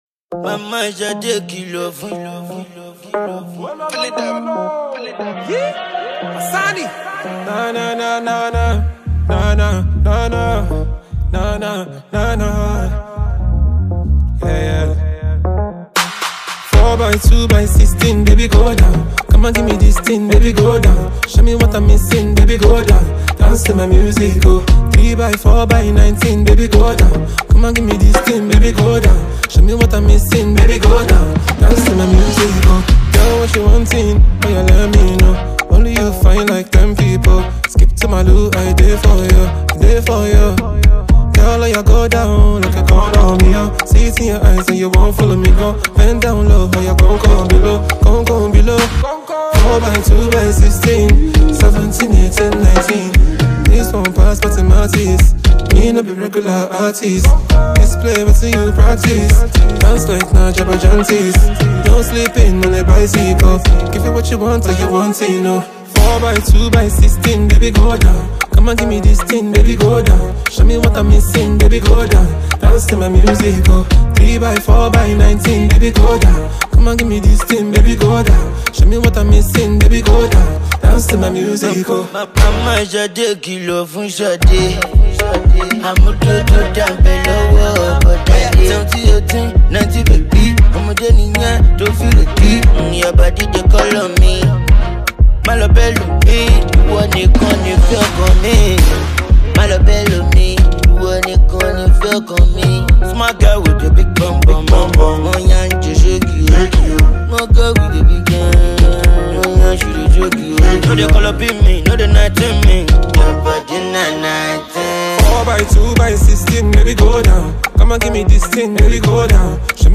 soulful Nigerian singer
street-hop sensation
genre-defying record